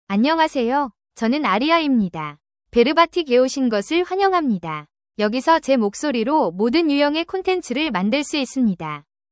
Aria — Female Korean (Korea) AI Voice | TTS, Voice Cloning & Video | Verbatik AI
Aria is a female AI voice for Korean (Korea).
Voice: AriaGender: FemaleLanguage: Korean (Korea)ID: aria-ko-kr
Voice sample
Listen to Aria's female Korean voice.